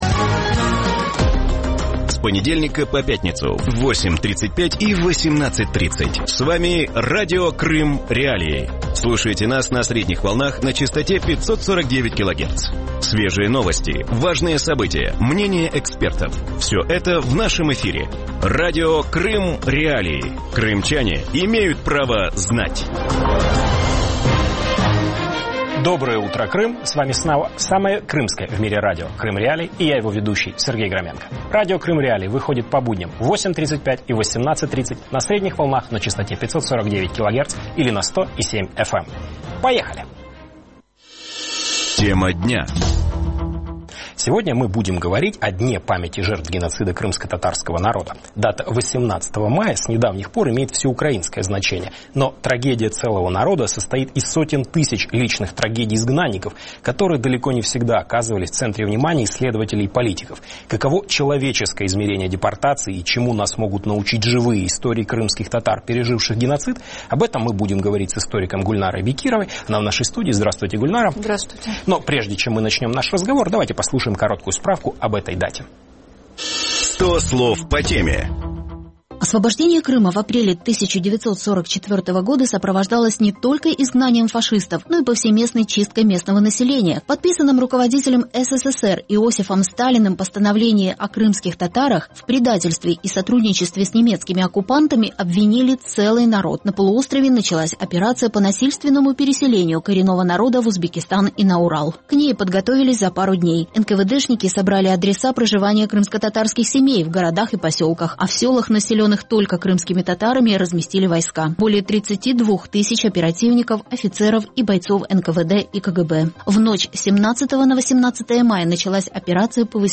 Утром в эфире Радио Крым.Реалии говорят о Дне памяти жертв геноцида крымскотатарского народа.